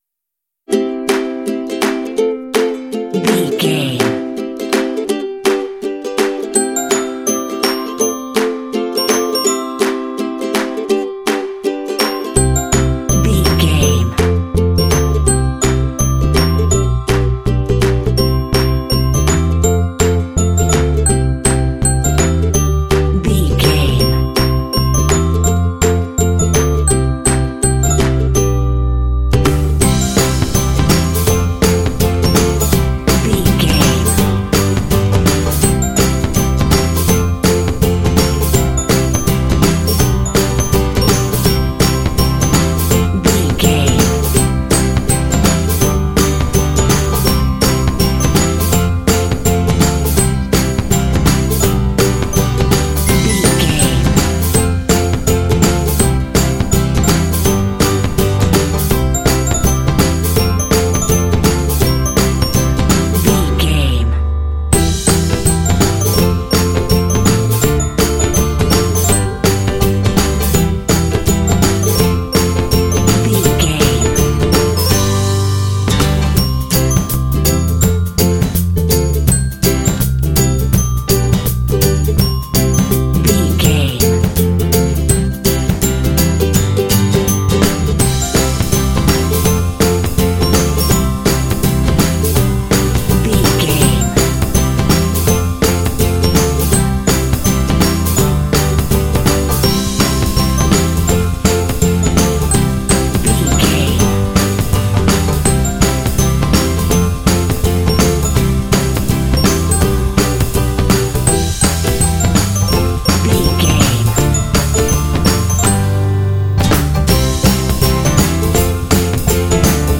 Uplifting
Ionian/Major
energetic
acoustic guitar
bass guitar
drums
piano
percussion
indie
pop
contemporary underscore